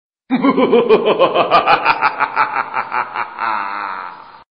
Categoria Effetti Sonori